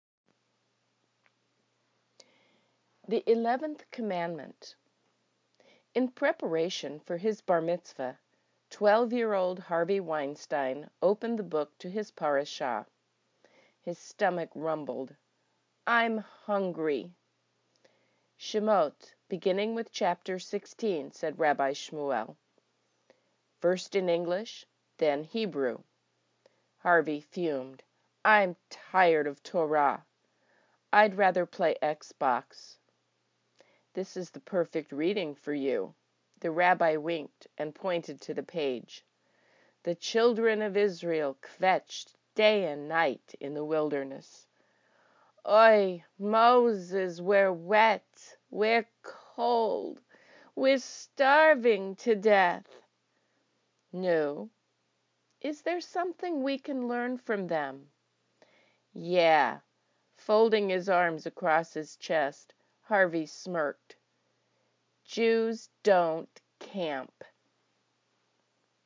The lobby of Congregation Beth Torah teemed with poets and their guests.